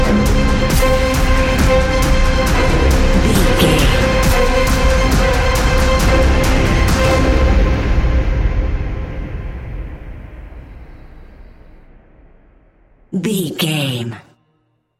Fast paced
In-crescendo
Ionian/Major
industrial
dark ambient
EBM
drone
synths
Krautrock